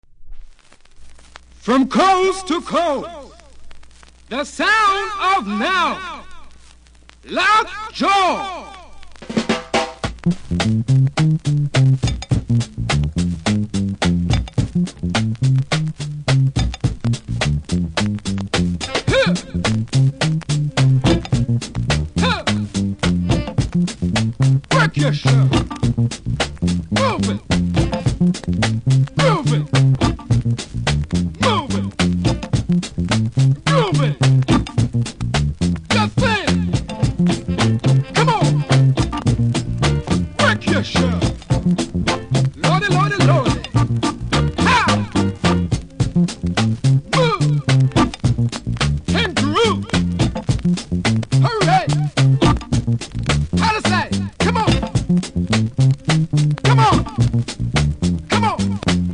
ジャマイカ盤なので多少のプレスノイズありますので試聴で確認下さい。